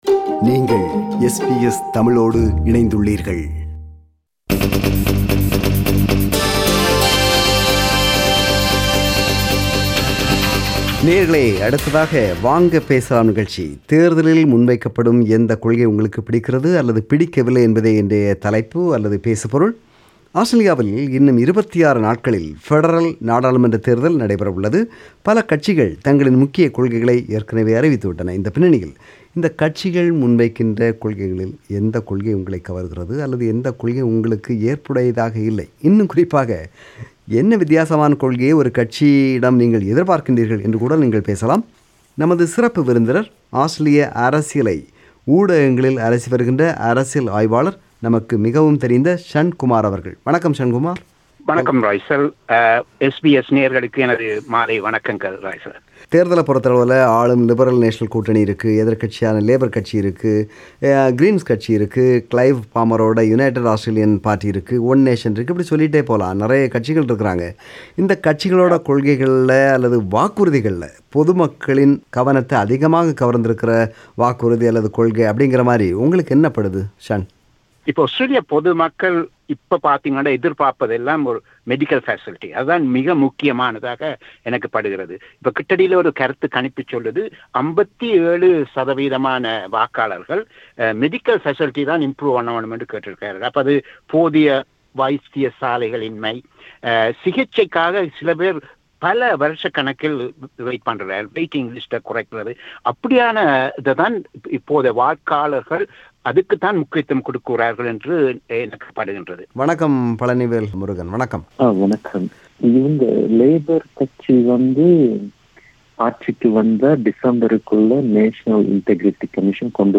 This is the compilation of views shared by our listeners in “Vanga Pesalam” program.